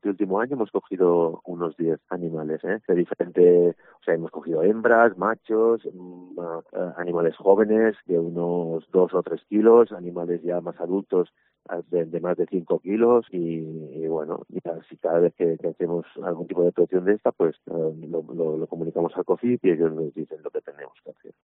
Llorenç Suau, concejal de Medio Ambiente de Andratx